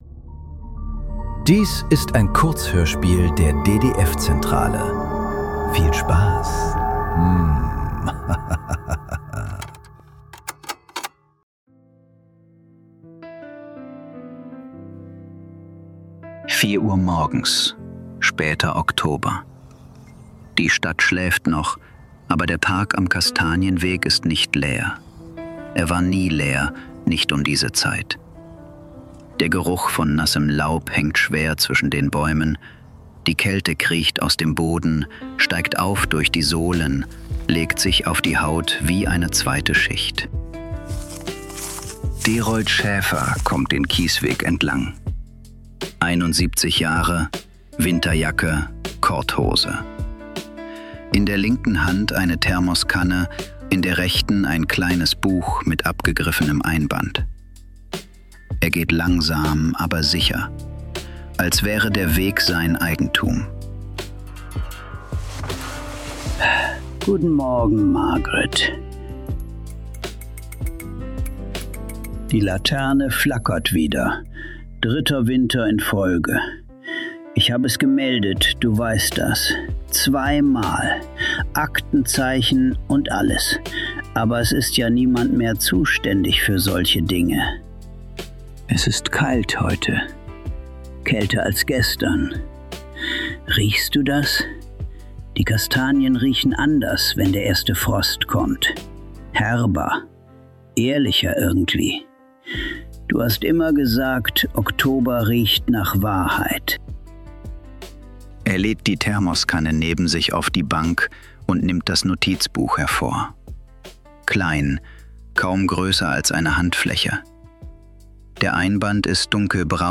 Die letzte Seite ~ Nachklang. Kurzhörspiele.